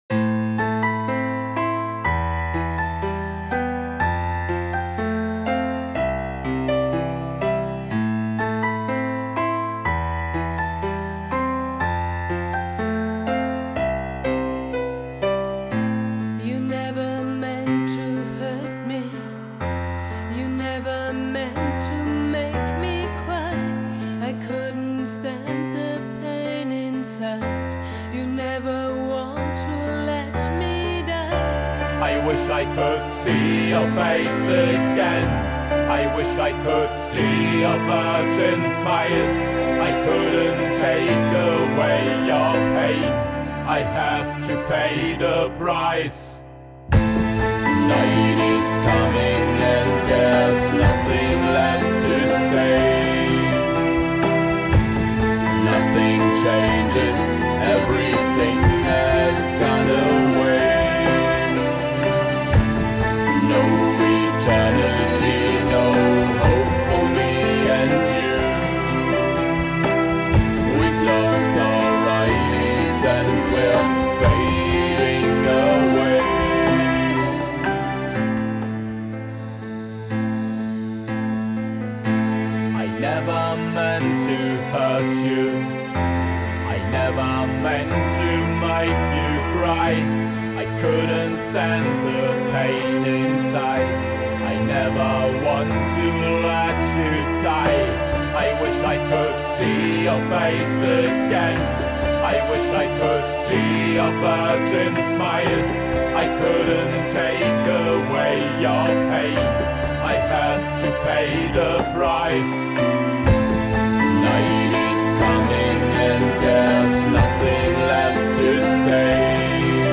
Darkwave